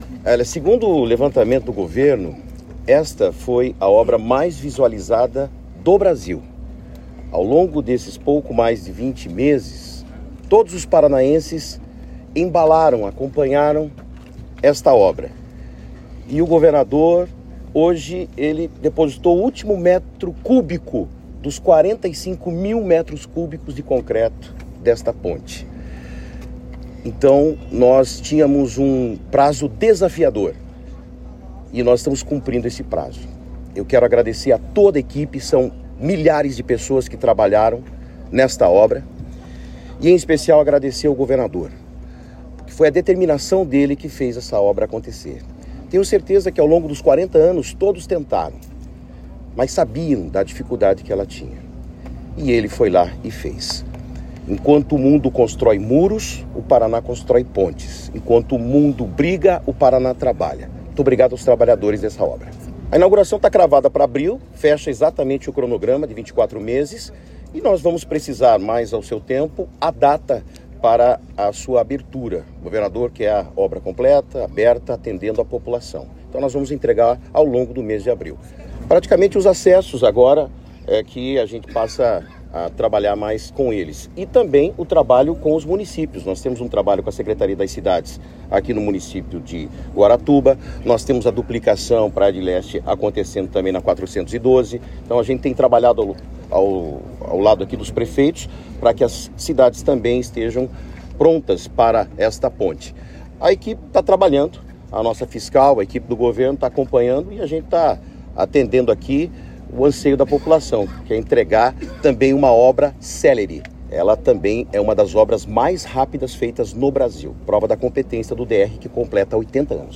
Sonora do secretário de Infraestrutura e Logística, Sandro Alex, sobre o "beijo" da Ponte de Guaratuba